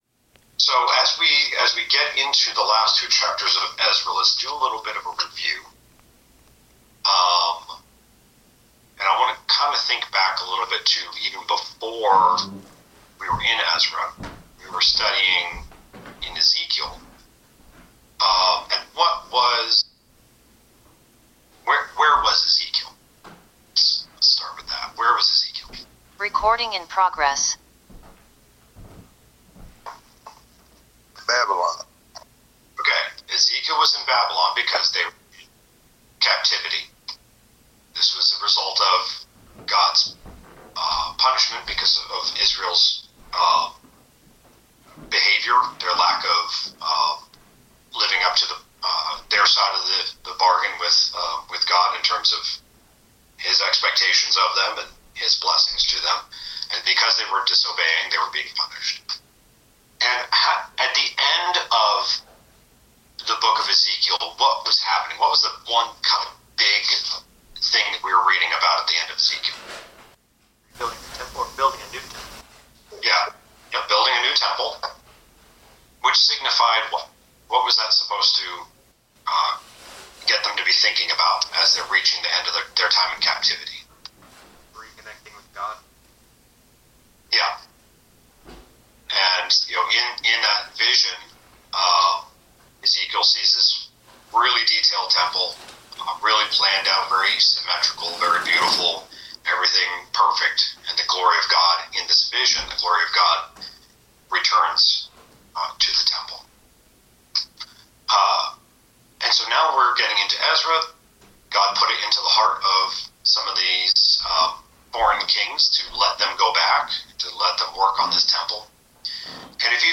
Bible class: Ezra 9-10
Service Type: Bible Class